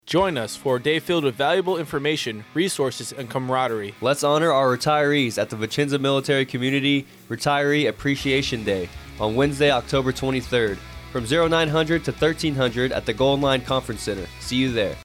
VMC Retiree Appreciation Day AFN Radio Spot